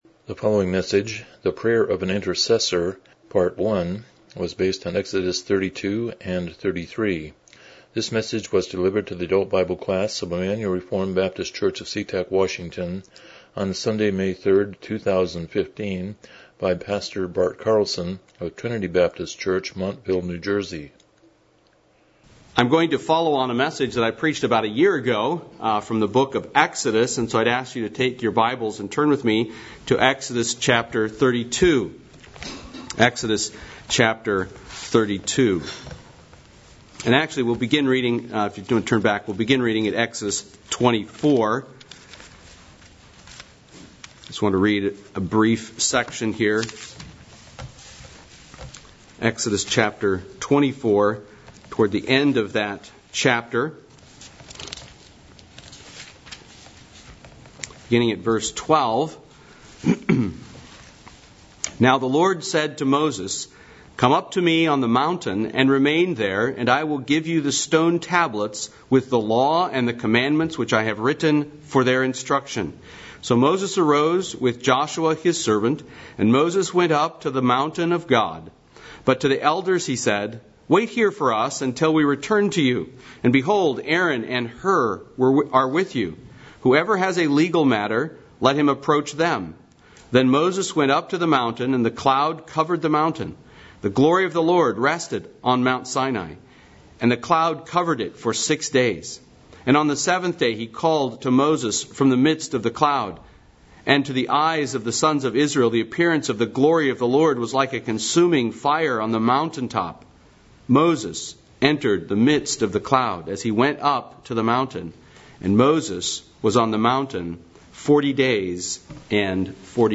Miscellaneous Service Type: Sunday School « The Preacher and His Preaching